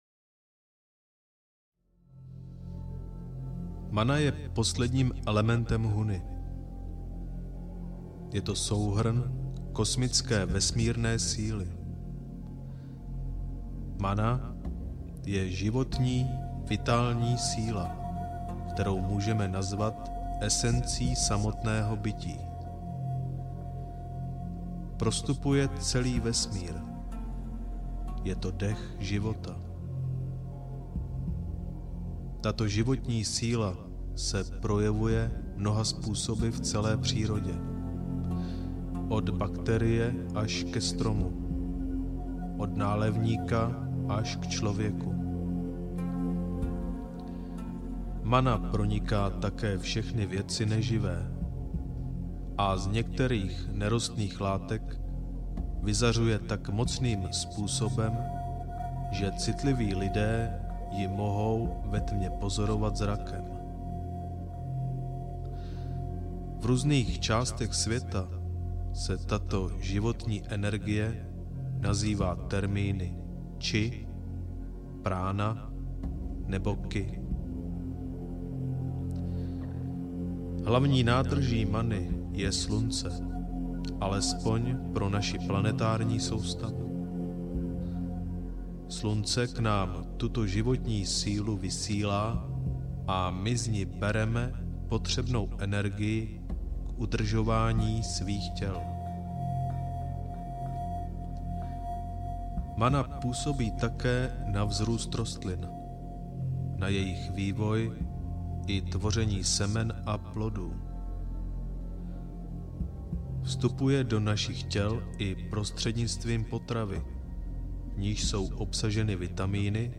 Huna – Tajemství audiokniha
Ukázka z knihy
huna-tajemstvi-audiokniha